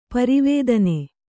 ♪ pari vēdane